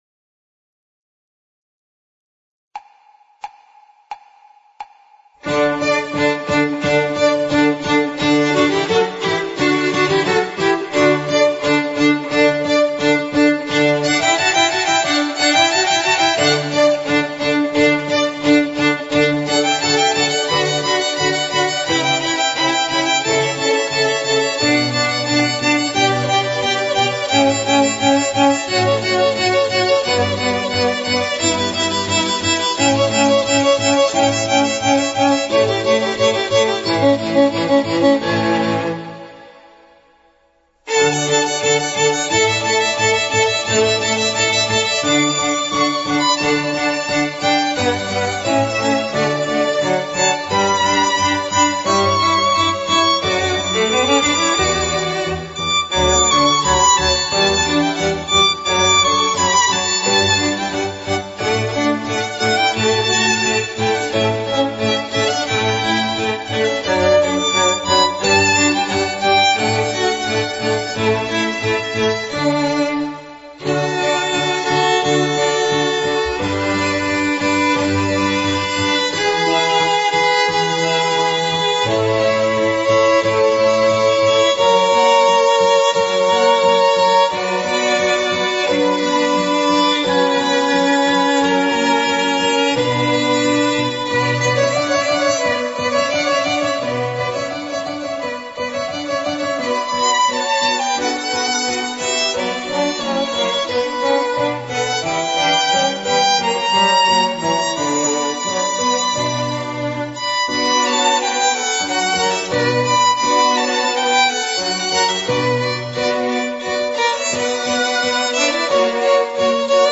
Instrumentation: Violin, Viola, Cello, Bass, Piano